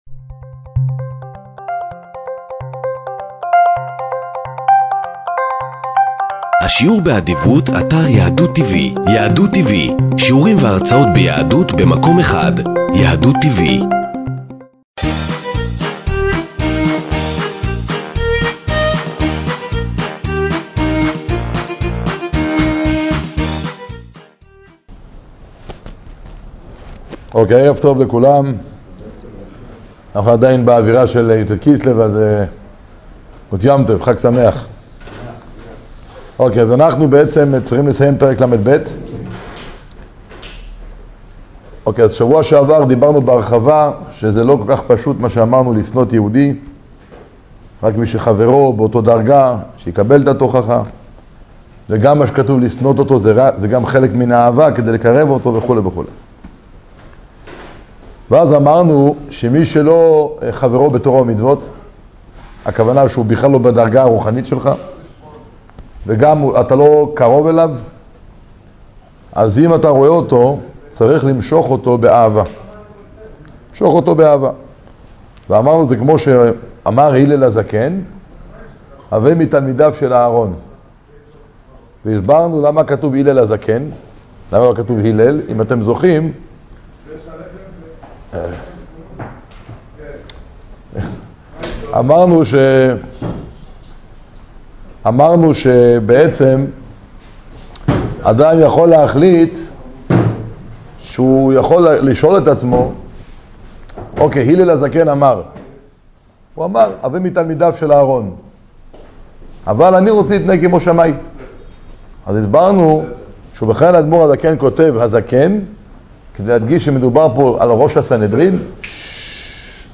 שיעור תניא